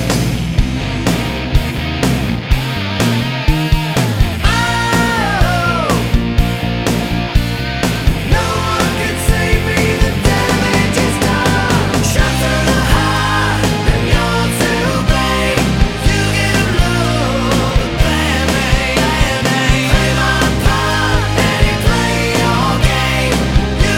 No Lead Guitar Rock 3:42 Buy £1.50